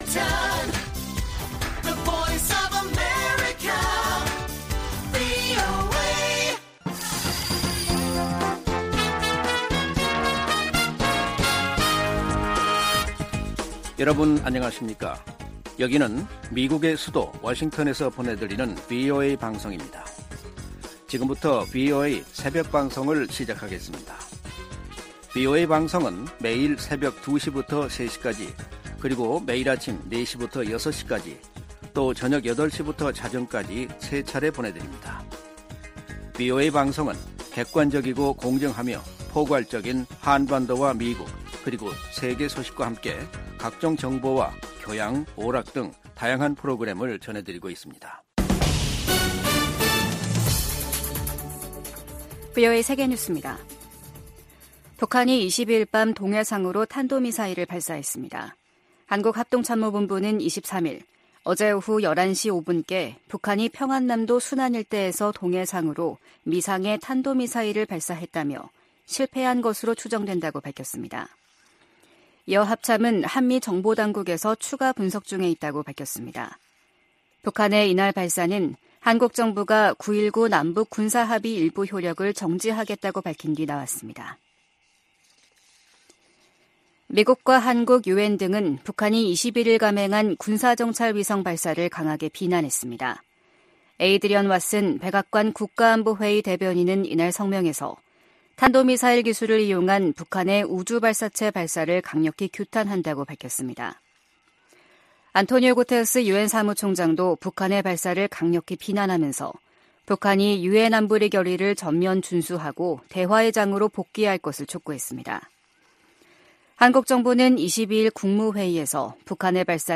VOA 한국어 '출발 뉴스 쇼', 2023년 11월 23일 방송입니다. 북한이 군사정찰위성 발사 궤도 진입 성공을 발표하자 미국은 강하게 규탄하고 동맹 방어에 필요한 모든 조치를 취하겠다고 밝혔습니다. 한국 정부는 9.19 남북 군사합의 일부 효력을 정지시켰습니다. 유엔과 유럽연합(EU) 등도 북한의 3차 군사정찰위성 발사가 안보리 결의 위반이라며 강력하게 규탄했습니다.